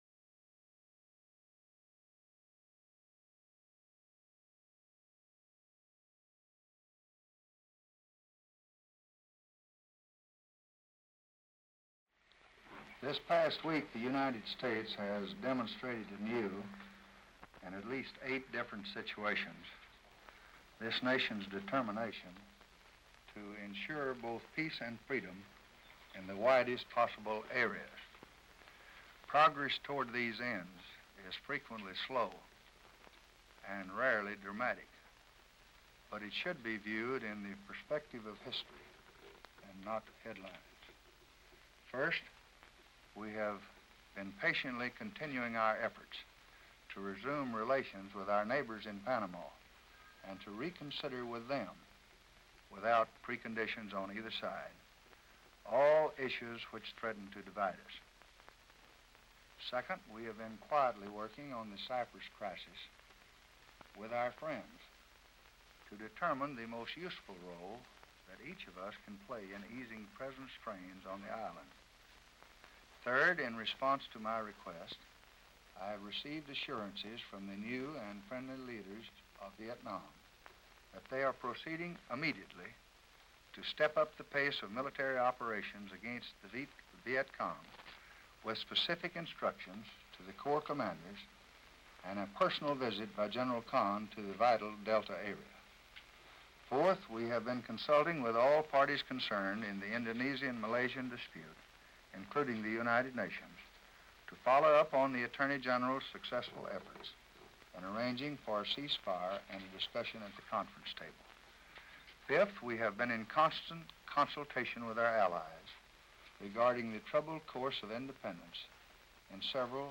February 1, 1964: Press Conference | Miller Center